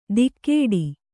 ♪ dikkēḍi